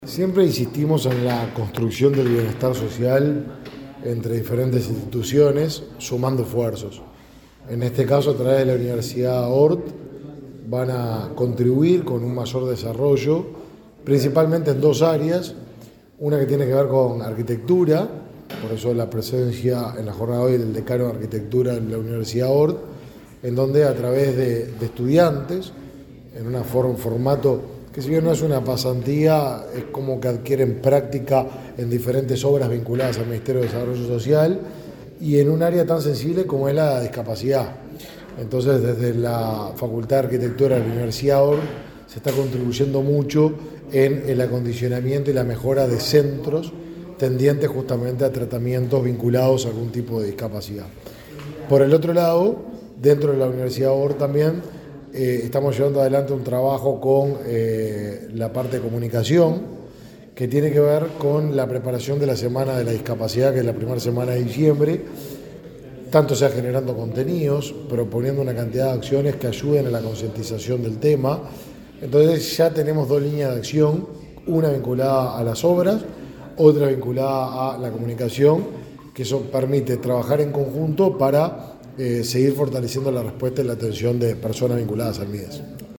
Declaraciones del ministro de Desarrollo Social, Martín Lema